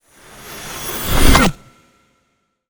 magic_conjure_charge2_01.wav